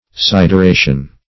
Search Result for " sideration" : The Collaborative International Dictionary of English v.0.48: Sideration \Sid`er*a"tion\, n. [L. sideratio.]